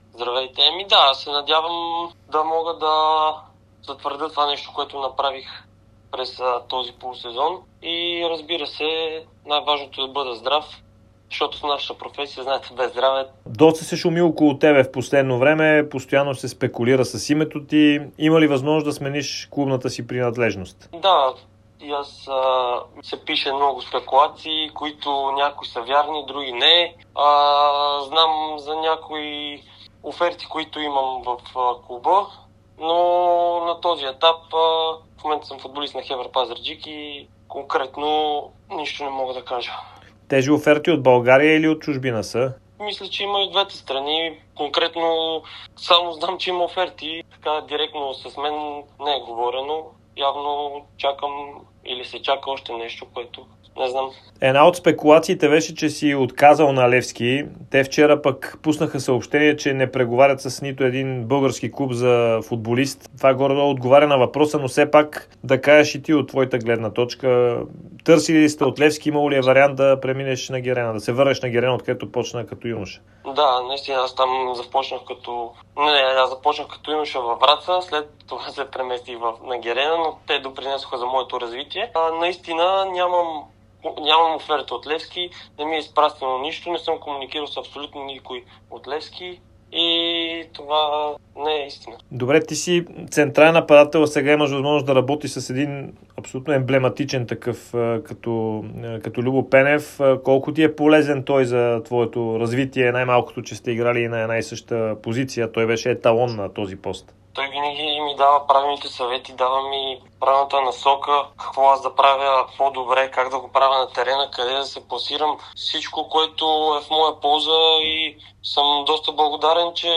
даде специално интервю за Дарик и dsport.